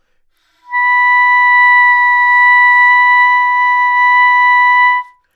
单簧管单音（吹得不好） " 单簧管 B5 糟糕的攻击空气
描述：在巴塞罗那Universitat Pompeu Fabra音乐技术集团的goodsounds.org项目的背景下录制。单音乐器声音的Goodsound数据集。
标签： 纽曼-U87 单簧管 单注 多样本 B5 好声音
声道立体声